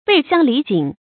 背鄉離井 注音： ㄅㄟˋ ㄒㄧㄤ ㄌㄧˊ ㄐㄧㄥˇ 讀音讀法： 意思解釋： 指遠離家鄉，流落他方。